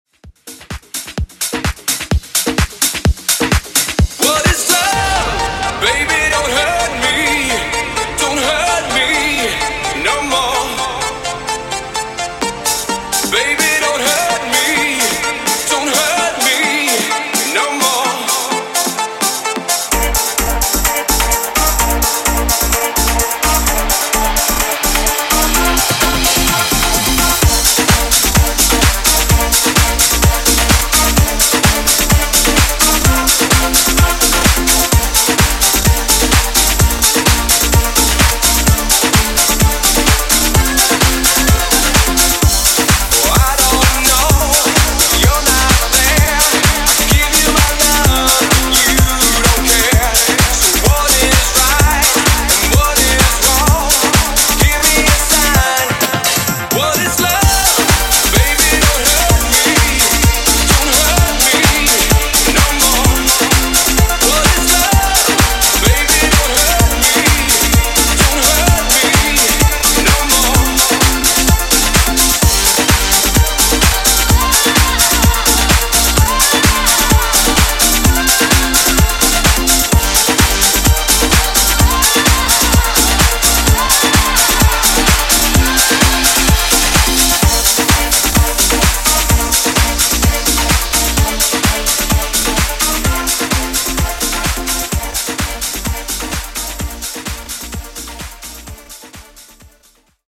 90s Extended House)Date Added